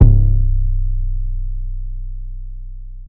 Dripp 808.wav